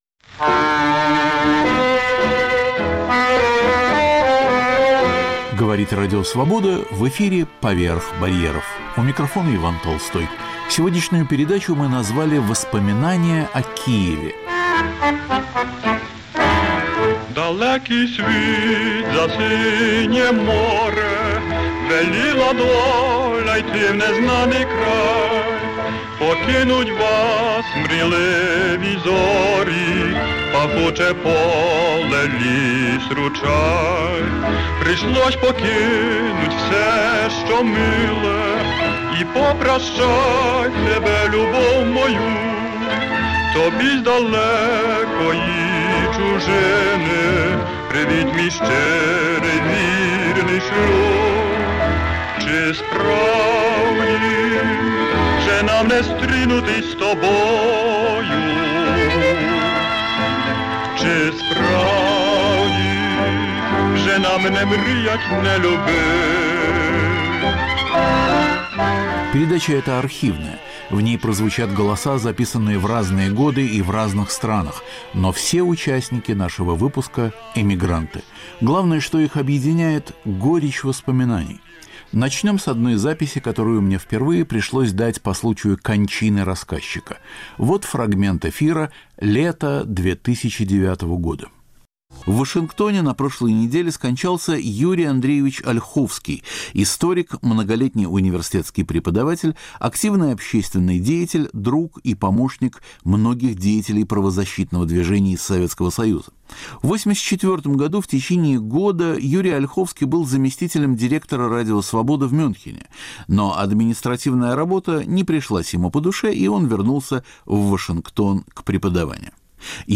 Воспоминания о Киеве. Радиопрограмма по архивным записям.